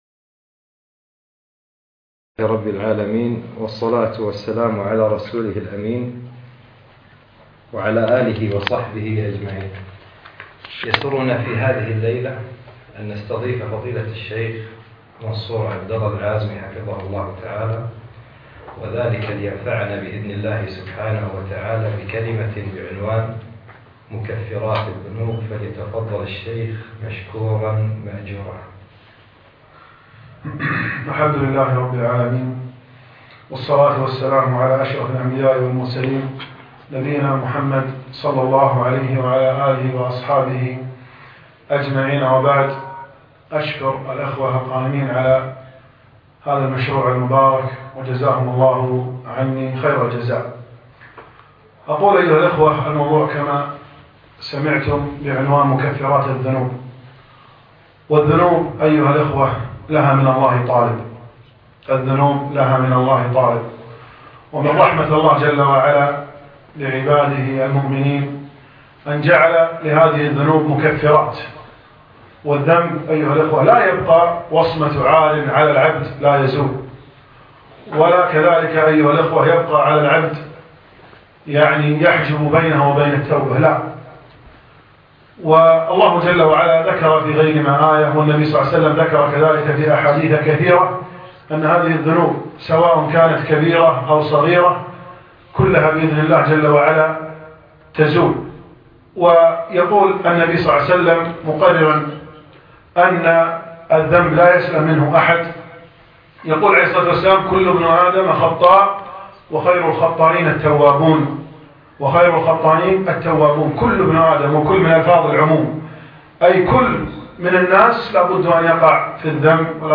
أقيمت المحاضرة بديوان مشروع الدين الخالص